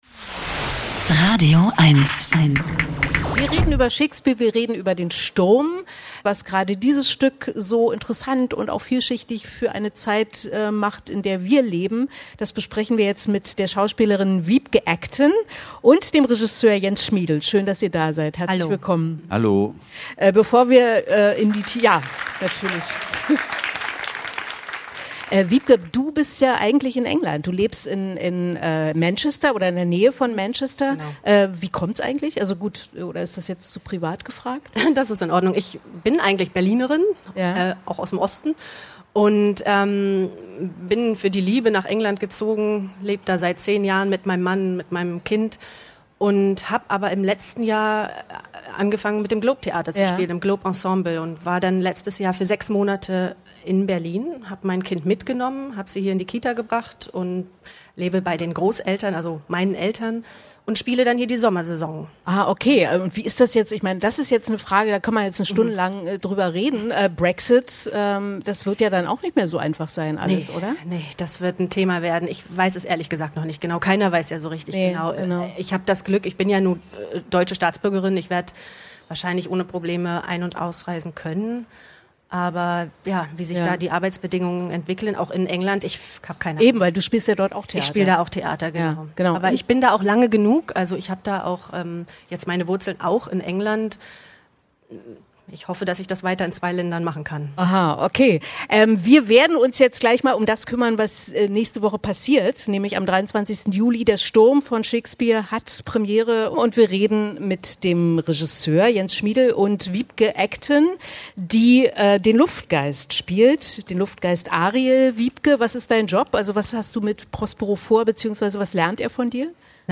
Radio-Eins-Interview.mp3